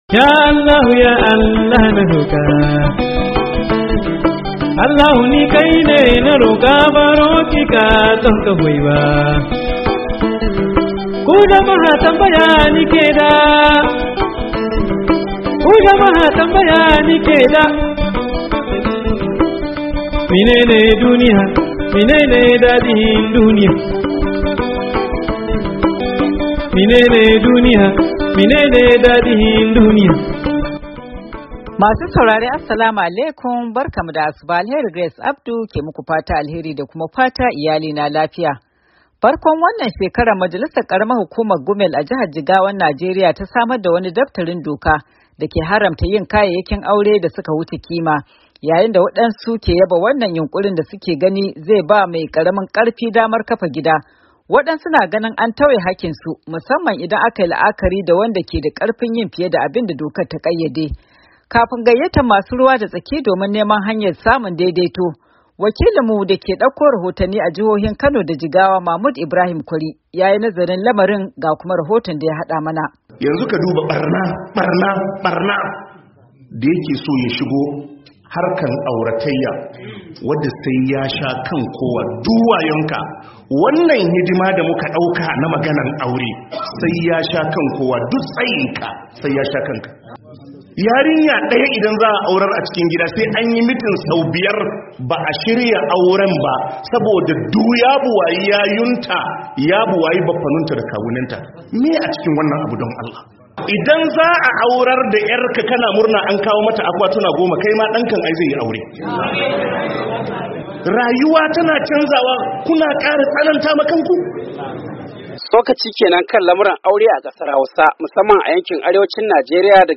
Saurari rahoto na musamman